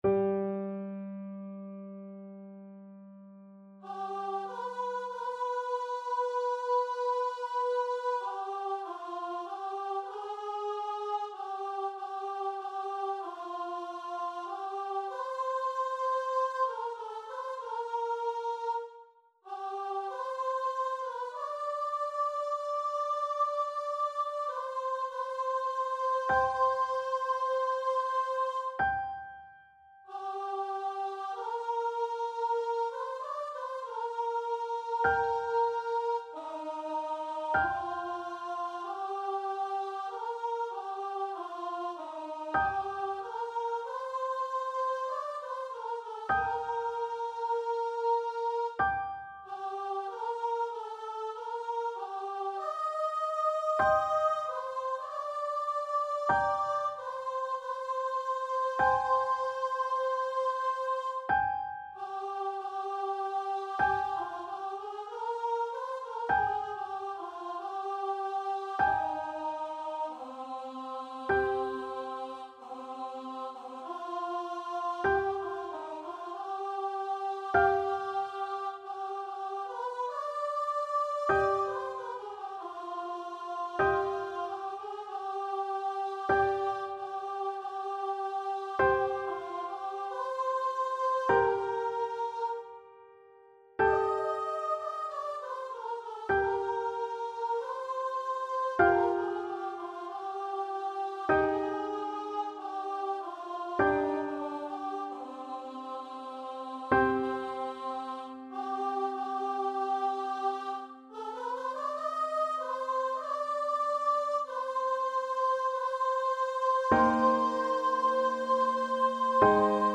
Lent =48
4/4 (View more 4/4 Music)
C5-G6
Classical (View more Classical Voice Music)